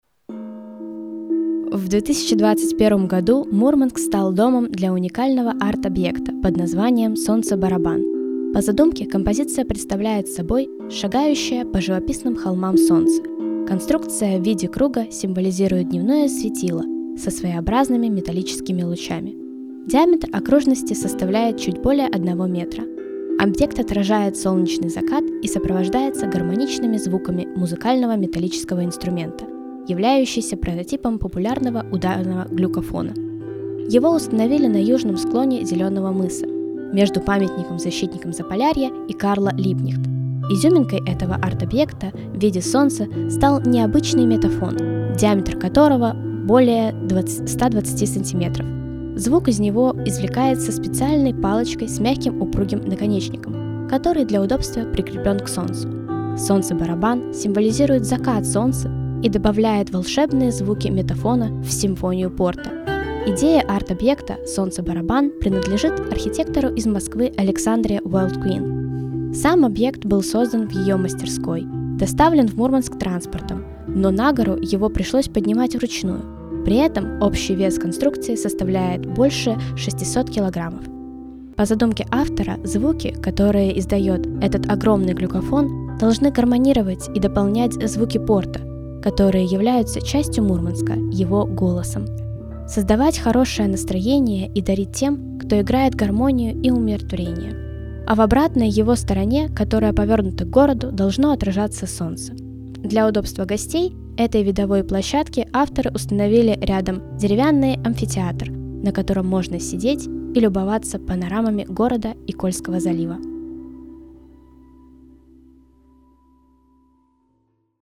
Очередная аудиоэкскурсия в рамках волонтерского туристического проекта «51 история города М»